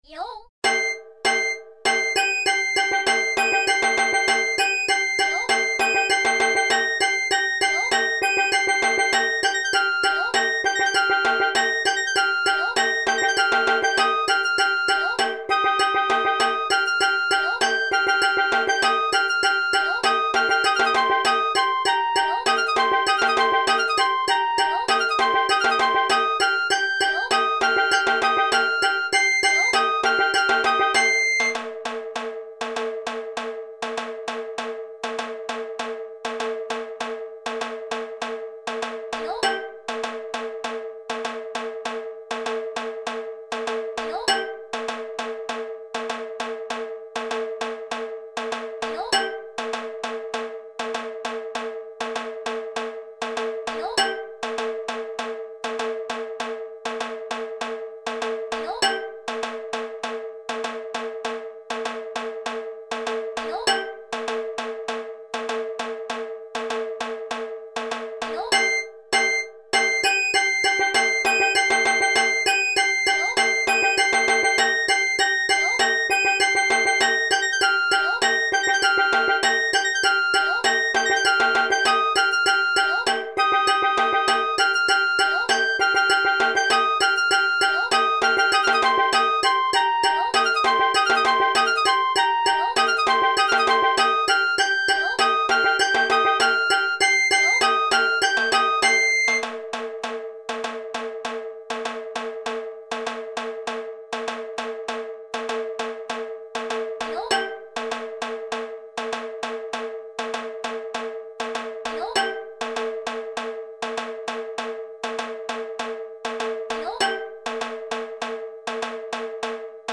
Tempo2（Tempo1とTempo3の中間）、Tempo3（実際の練習ベース）
02tyukan.mp3